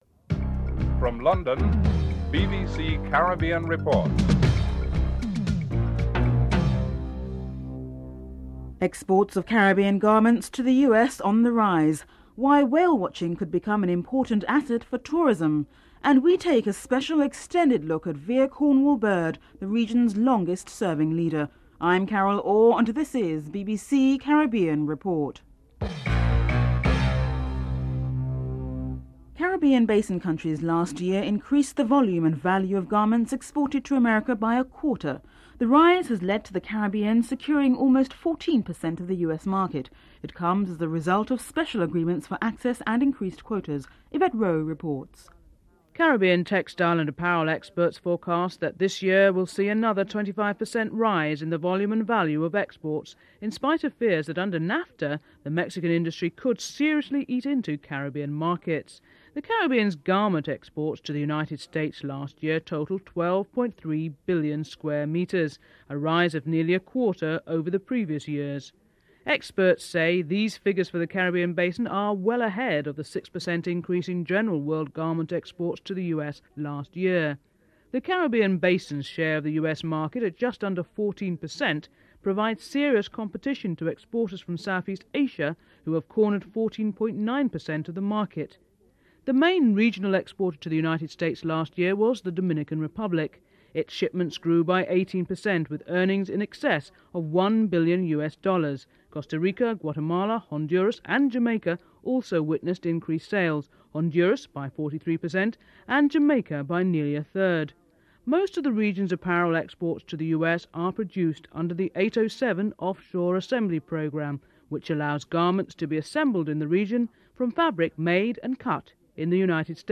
This and the succeeding segments features interviews with VC Bird and those who knew him (06:10-06:59)
12. Wrap up and theme music (14:52-15:03)